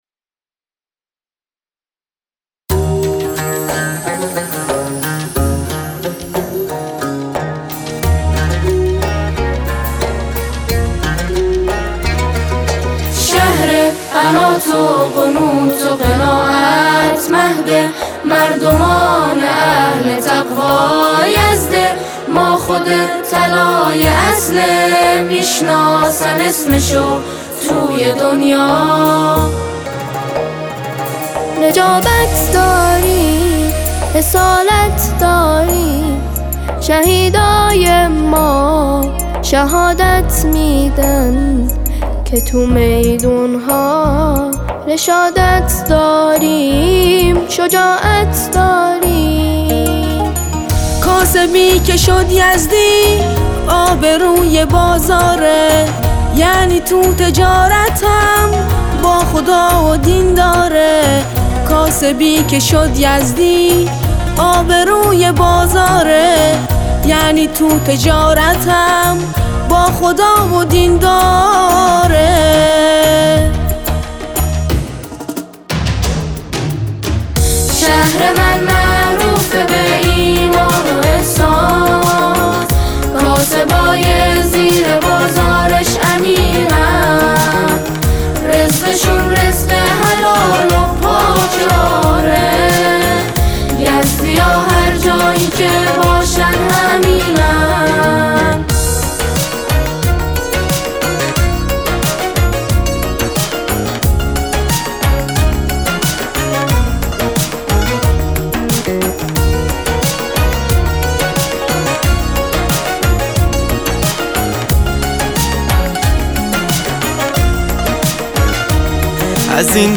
با اجرای پرشور